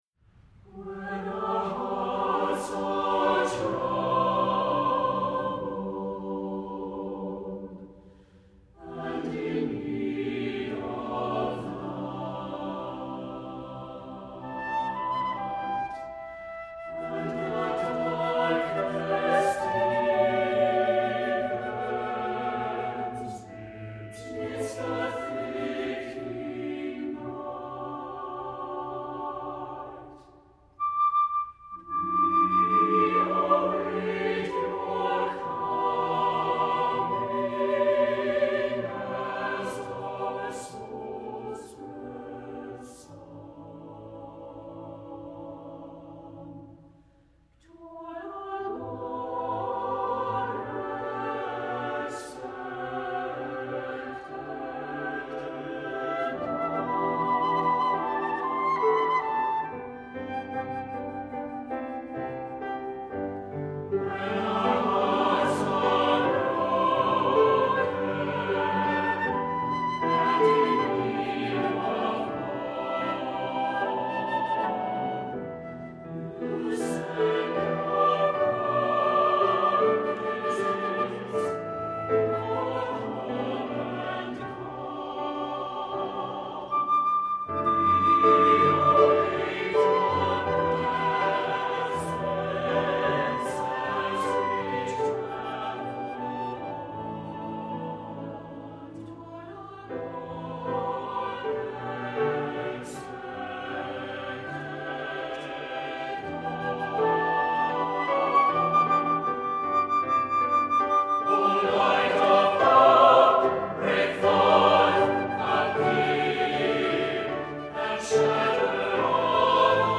Voicing: SATB, Flute, and Piano